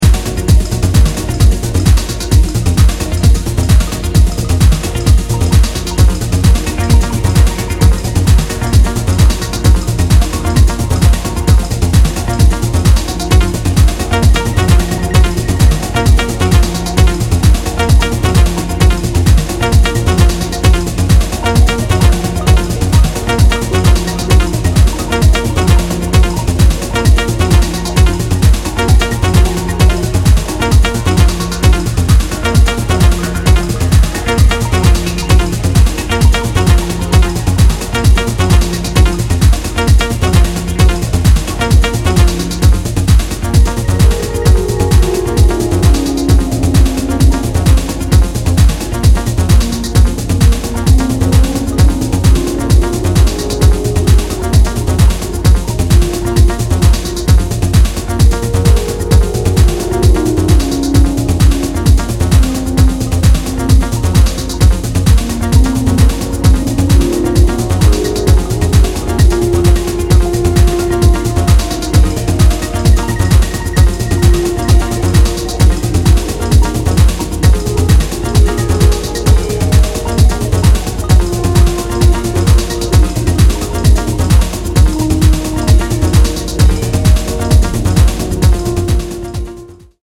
鮮やかなシンセリフや幽玄なパッドのレイヤー、ダスティなリズムの反復で叙情的なビルドアップを繰り広げるA-1